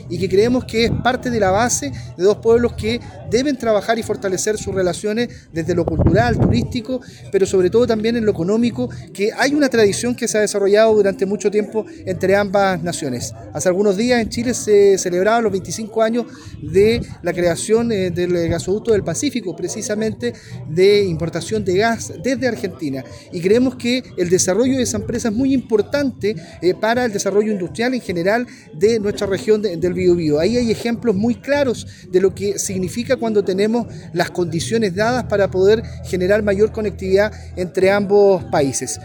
Durante la ceremonia, el delegado destacó la relevancia del trabajo conjunto entre las dos naciones para garantizar una conexión segura y eficiente.